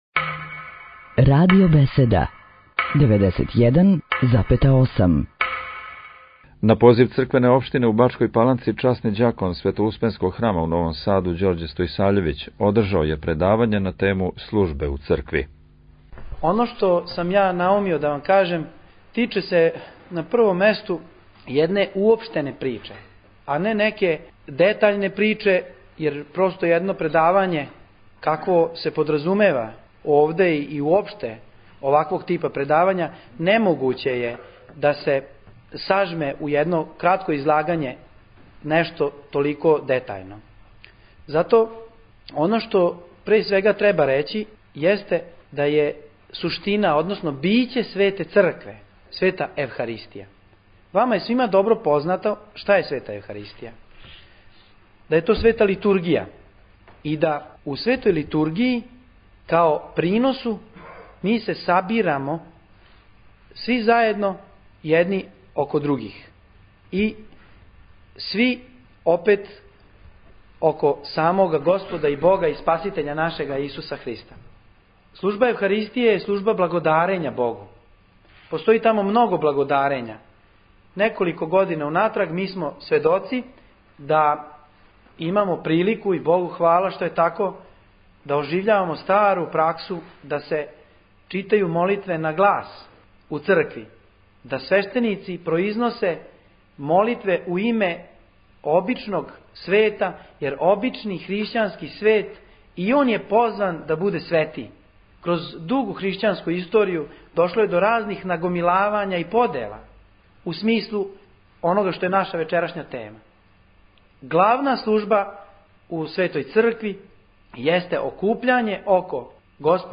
Бачка Паланка